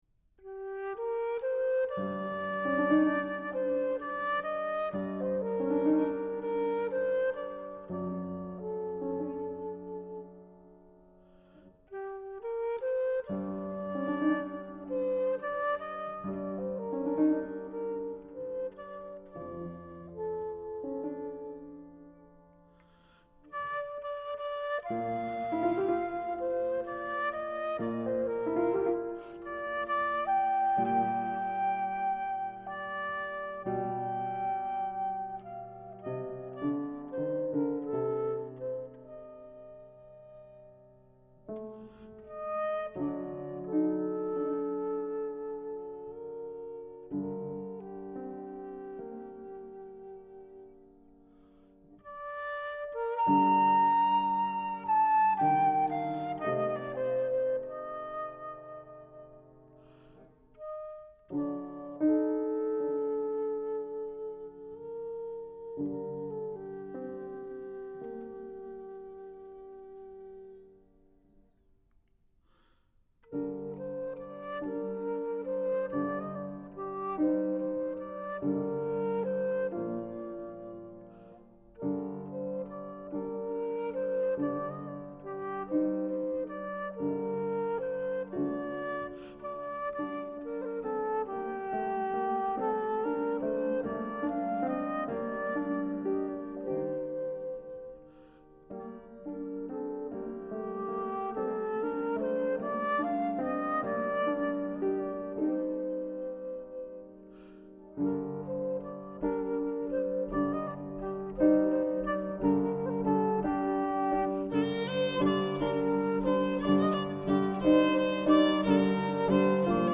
Airs populaires harmonisés
soprano
violon
vielle à roue, chant
piano